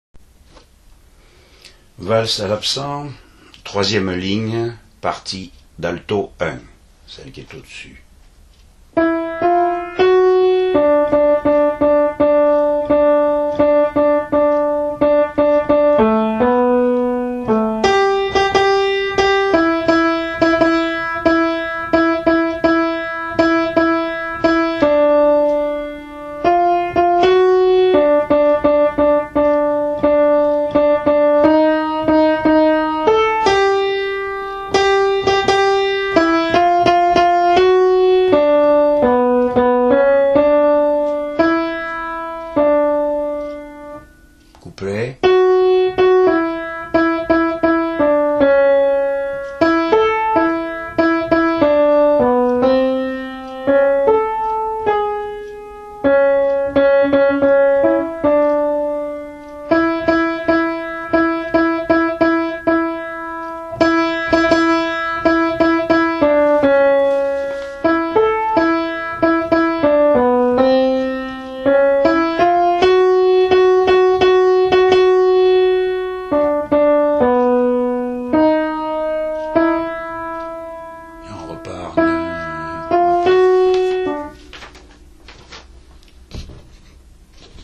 Valse À L’absent Alto 1